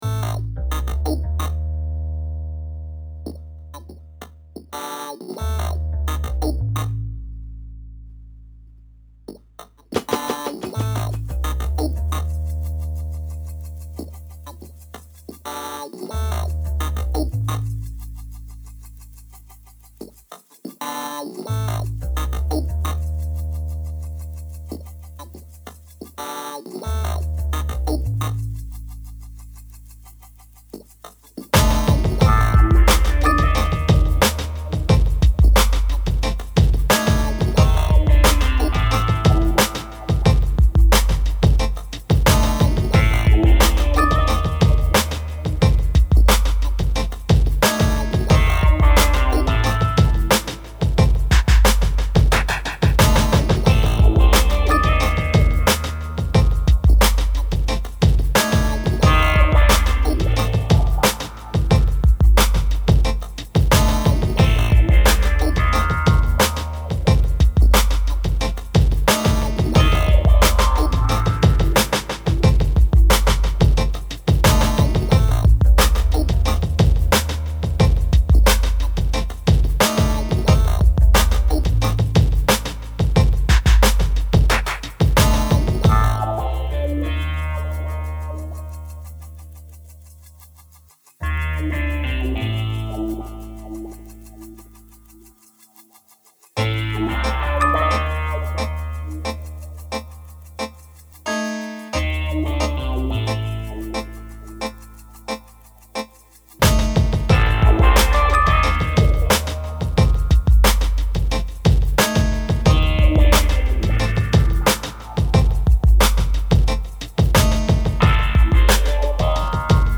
70s blaxploitation with a funky laidback hip hop beat.